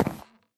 Sound / Minecraft / step / wood2.ogg
wood2.ogg